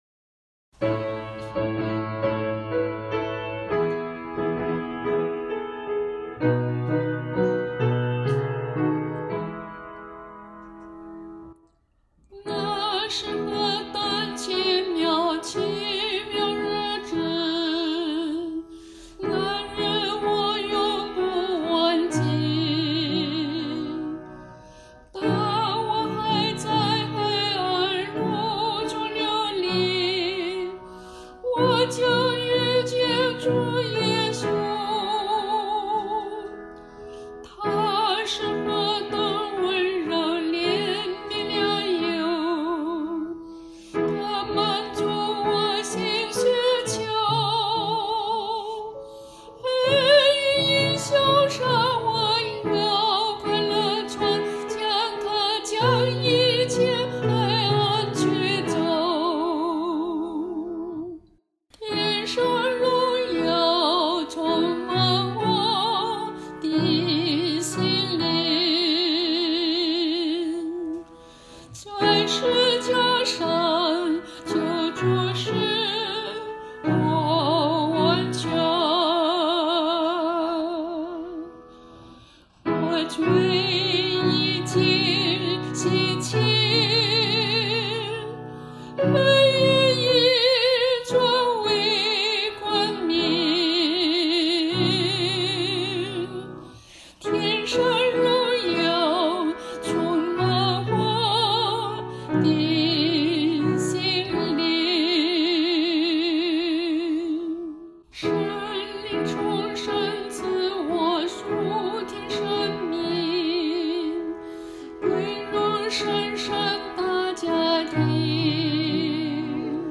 示唱